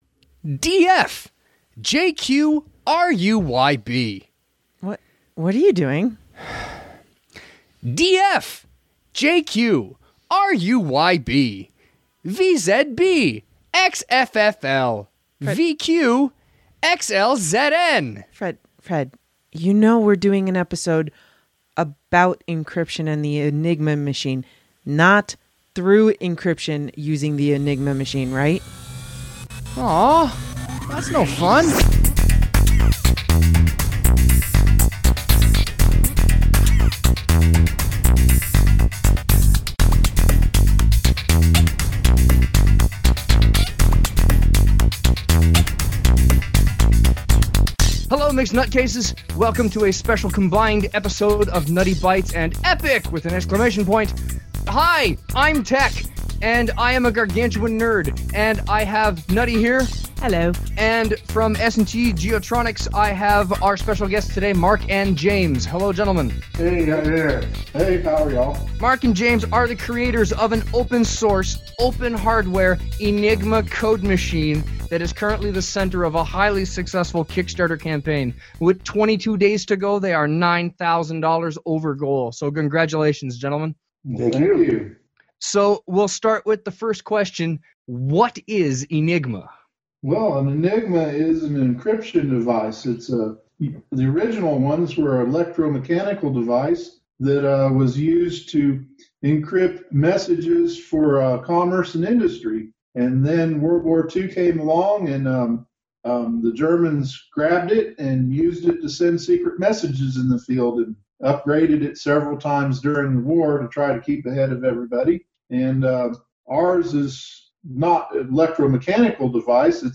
(Epic) Interview 3: Open Enigma